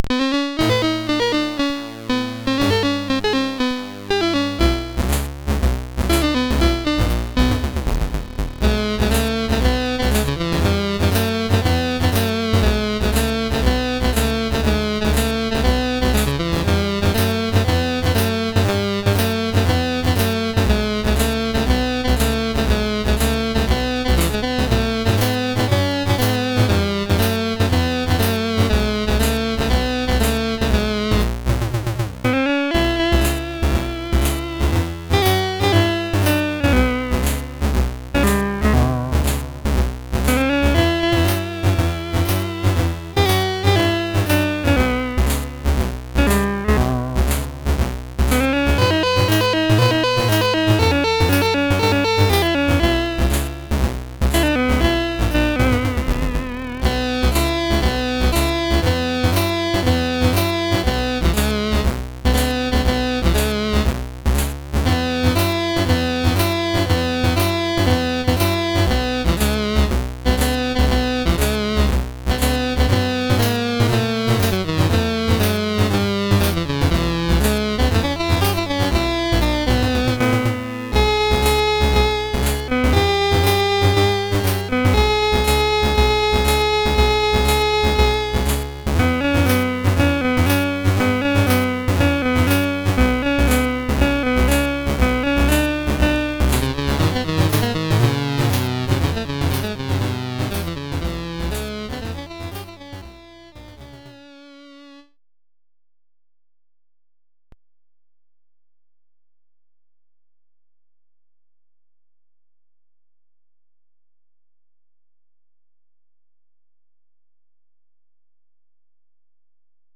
Commodore SID Music File